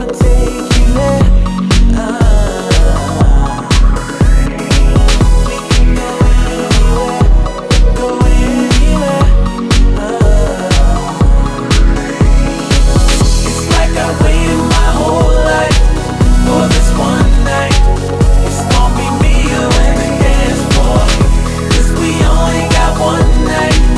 no Backing Vocals Dance 4:34 Buy £1.50